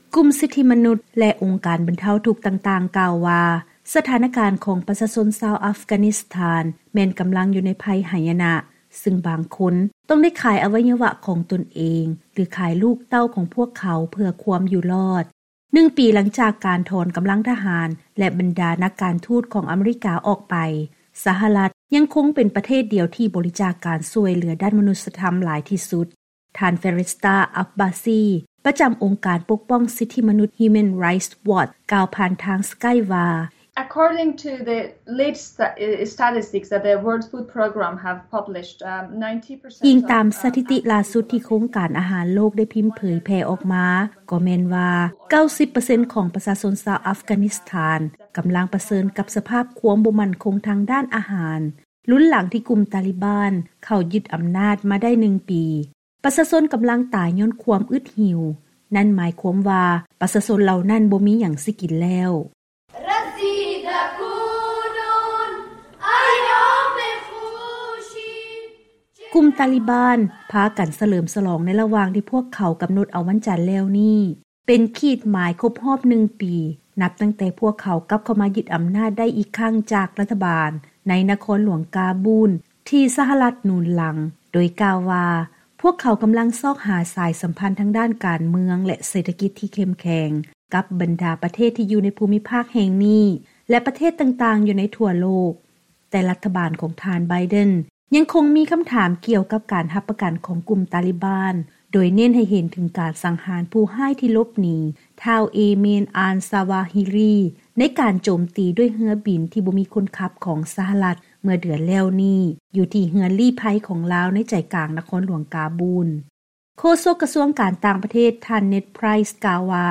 ເຊີນຮັບຟັງບົດລາຍງານກ່ຽວກັບ ຄວາມກັງວົນໃຈຂອງ ສະຫະລັດ ໃນການປ່ອຍຊັບສິນຄືນໃຫ້ແກ່ ອັຟການິສຖານ